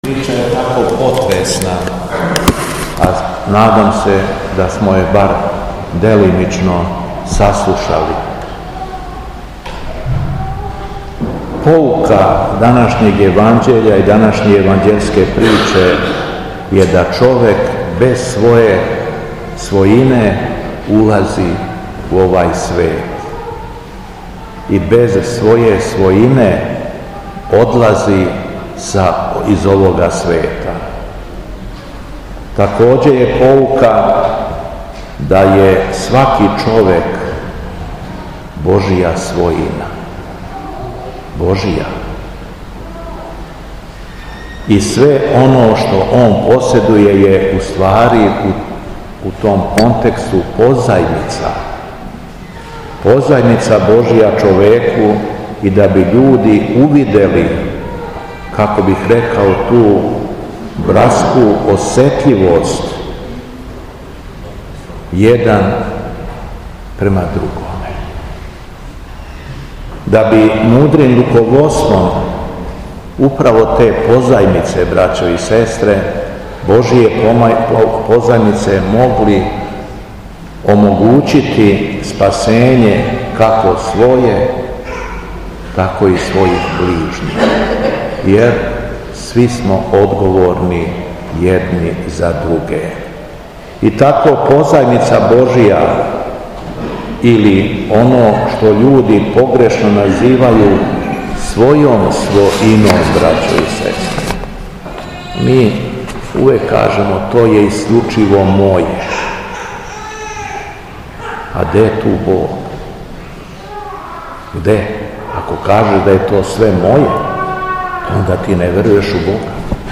Беседа Његовог Високопреосвештенства Митрополита шумадијског г. Јована
У недељу, 24. новембра 2024. године, на празник Светог великомученика Мине и Светог Стефана Дечанског, Његово Високопреосвештенство Архиепископ крагујевачки и Митрополит шумадијски Господин Јован служио је Свету Литургију у Саборном храму Успења Пресвете Богородице у Крагујевцу.